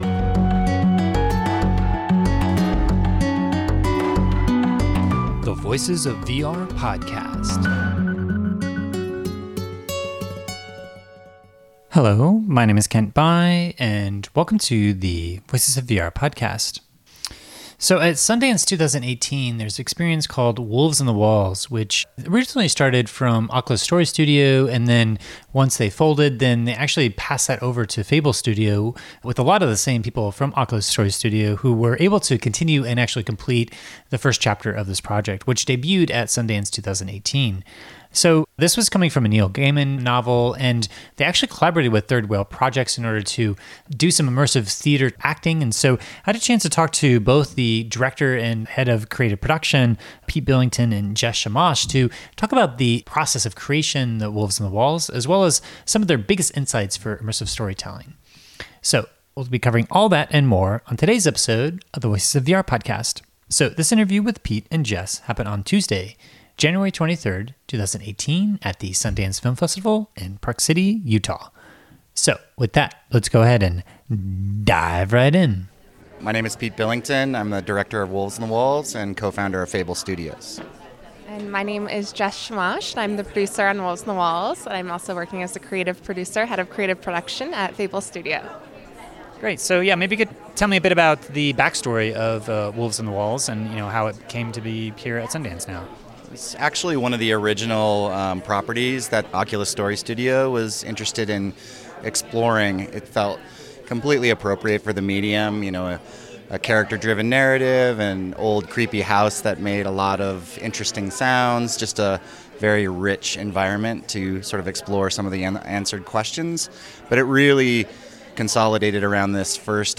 at their Sundance premiere last year in 2018 to talk about they combined insights from film, video game design, and immersive theater in the creation of Wolves in the Walls, which is now available.